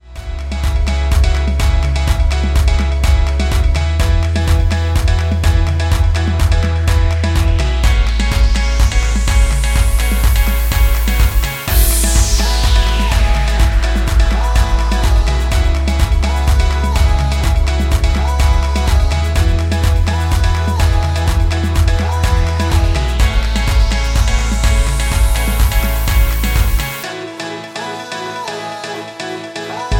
MPEG 1 Layer 3 (Stereo)
Backing track Karaoke
Pop, 2010s